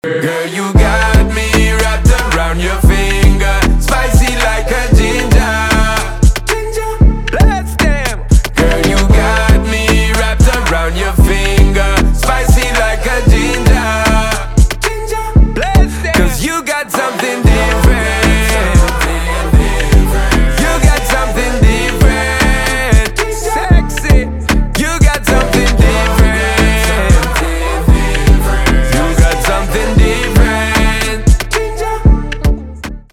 реггетон
басы